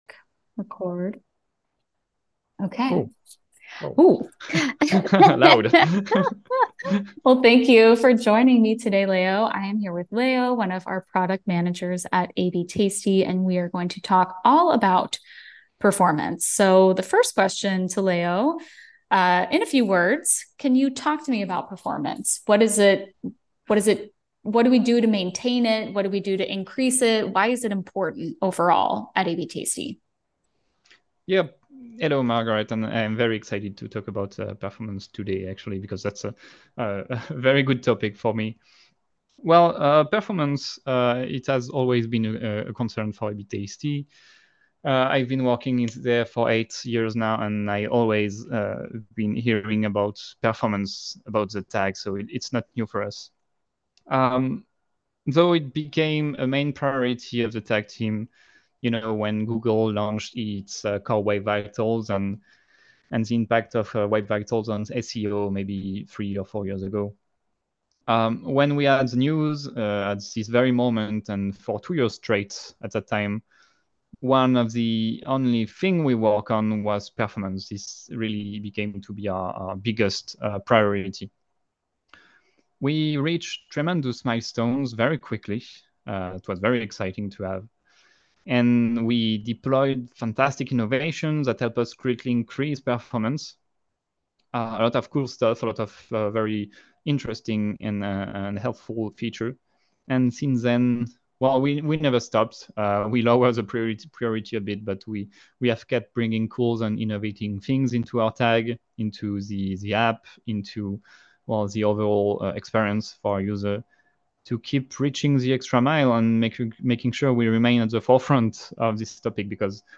insightful discussion